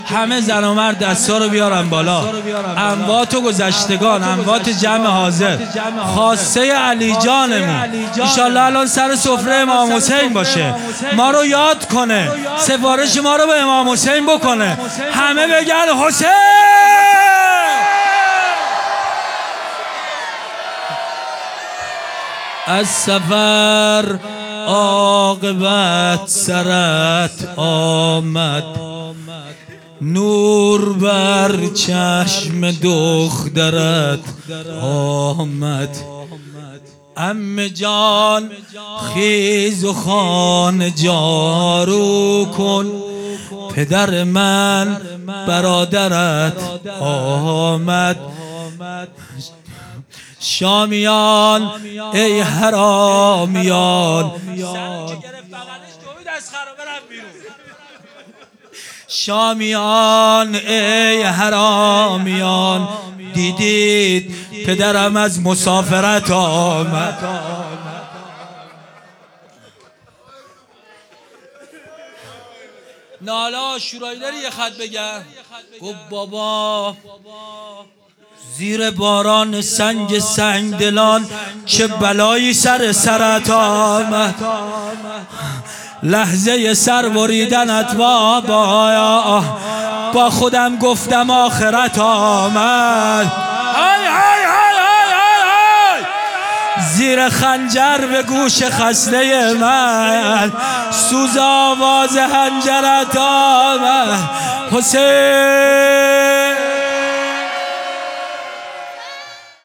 خیمه گاه - هیئت محبان الحسین علیه السلام مسگرآباد - روضه پایانی